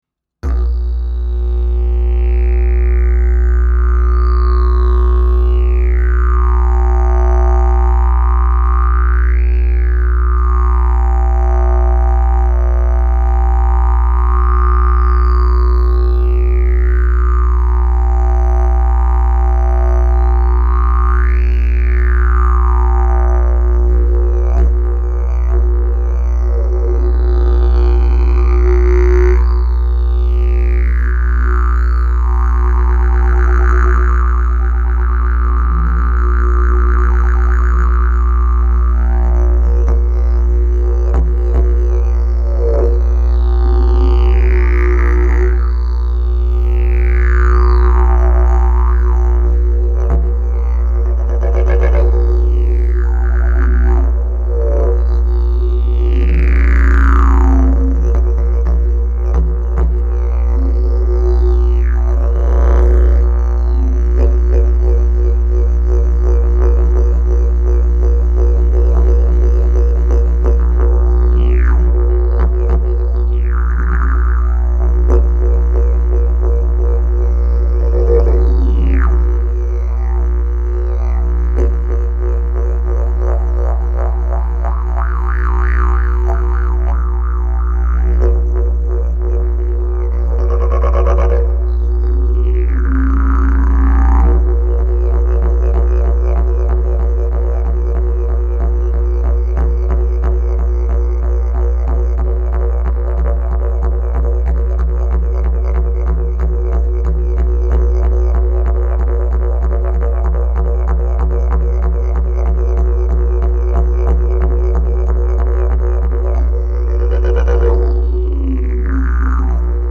Key: B Length: 65" Bell: 4" Mouthpiece: Walnut. Mesquite, Padauk Back pressure: Very strong Weight: 7.6 lbs Skill level: Any
Didgeridoo #598 Key: B